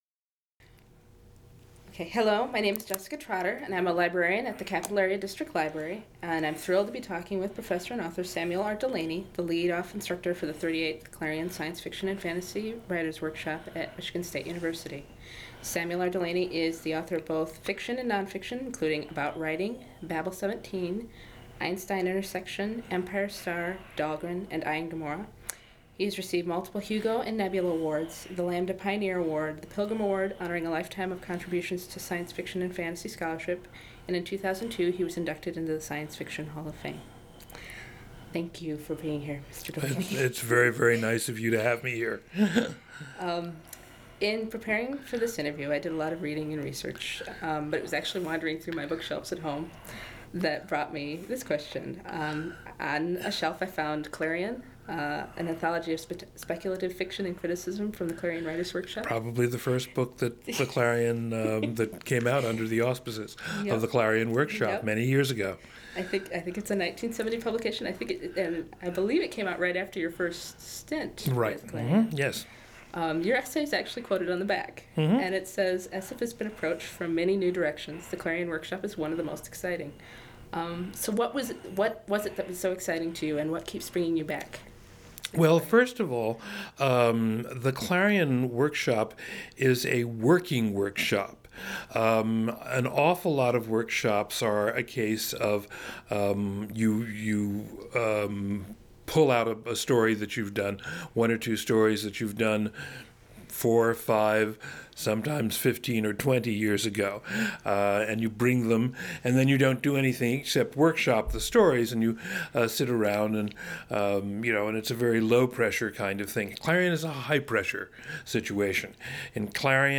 Author Samuel Delaney talks about his participation in the 38th annual Clarion Science Fiction and Fantasy Writers Workshop at Michigan State University. Delaney comments on the work done by the students, and the impact which the workshop might have on their careers. He also talks about his own writing career, teaching, who influenced him, and why he portrays mundane and often vulgar acts in his books.